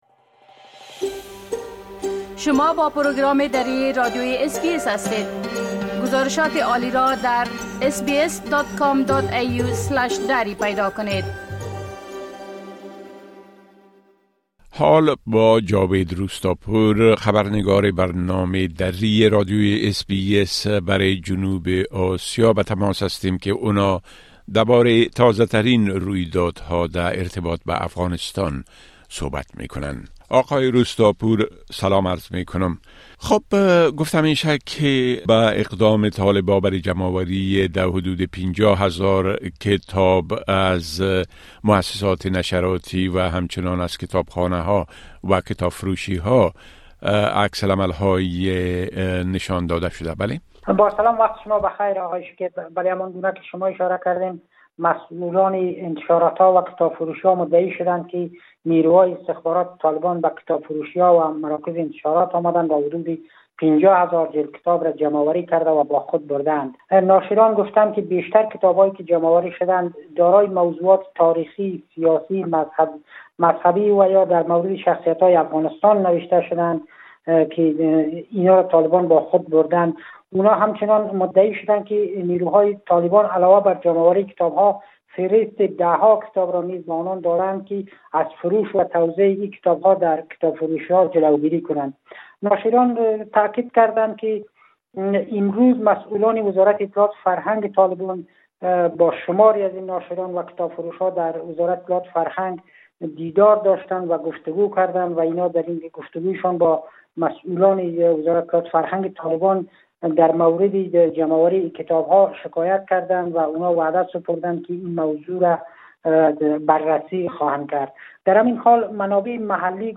گزارش كامل خبرنگار ما، به شمول اوضاع امنيتى و تحولات مهم ديگر در افغانستان را در اينجا شنيده مى توانيد.